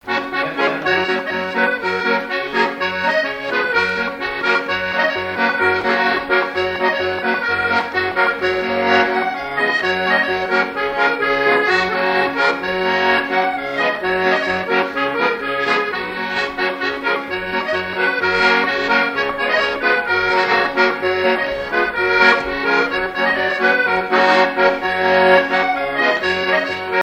Genre brève
Mission Ile-d'Yeu
Témoignages sur la pêche, accordéon, et chansons traditionnelles
Pièce musicale inédite